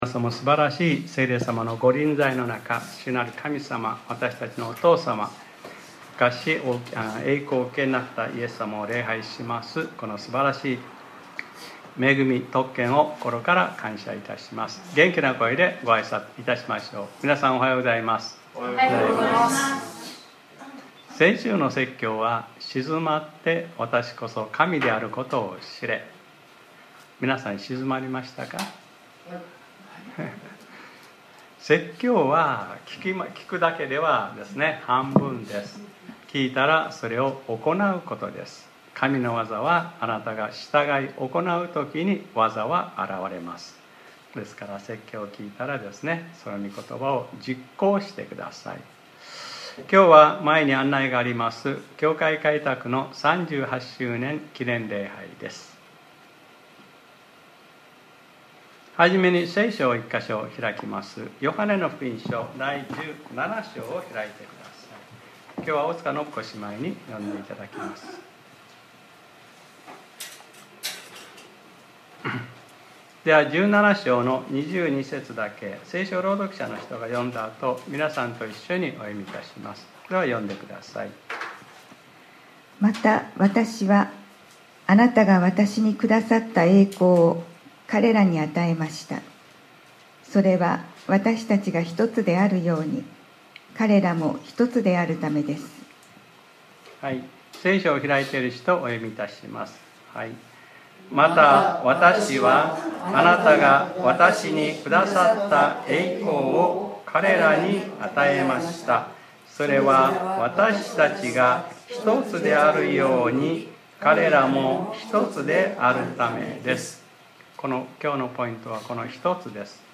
2025年10月05日（日）礼拝説教『 開拓記念礼拝：ネットワーク 』 | クライストチャーチ久留米教会